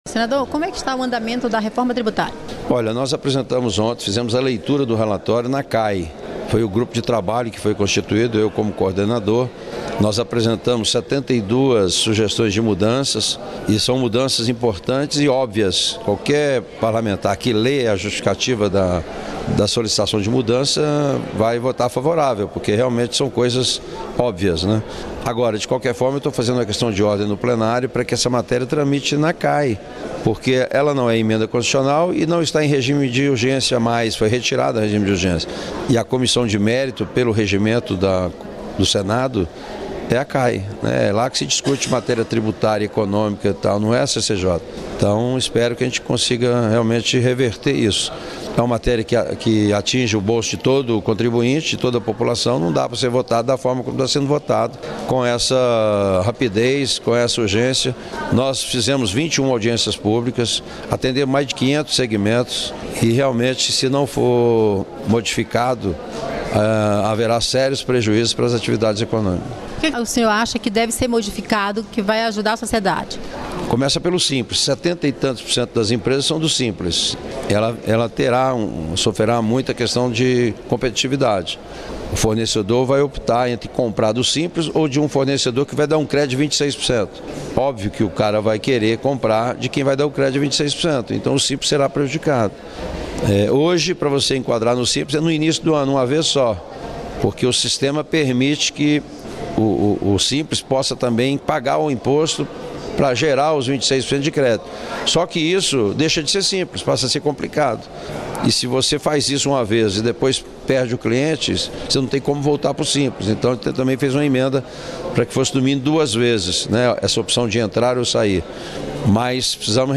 O senador Izalci Lucas (PL-DF), coordenador do grupo de trabalho criado para tratar da Reforma Tributária, quer que a discussão da reforma passe pela Comissão de Assuntos Econômicos (CAE) antes de seguir para a Comissão de Constituição e Justiça (CCJ). Em entrevista à TV Senado, ele afirmou que é preciso uma análise detalhada da reforma e não há pressa para votação.